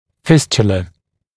[‘fɪstjələ] [-ʧə-][‘фистйэлэ], [-чэ-]фистула, свищ